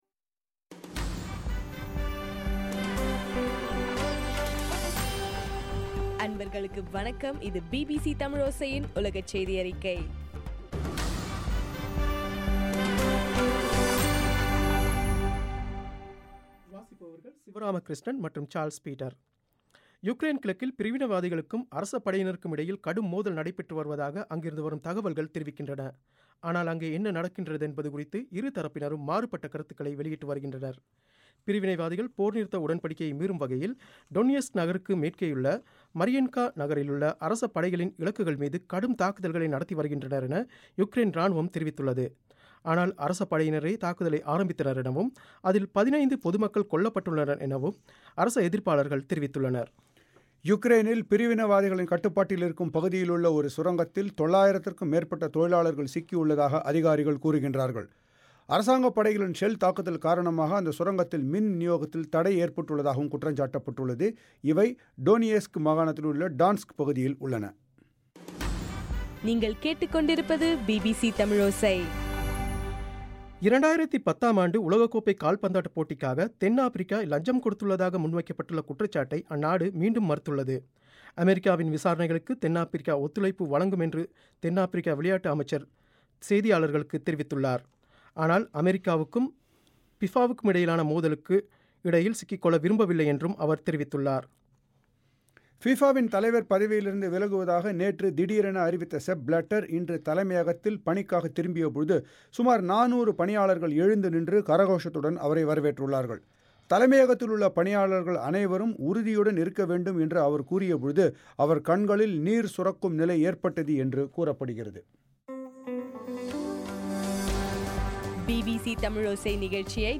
ஜூன் 3 2015 பிபிசி தமிழோசையின் உலகச் செய்திகள்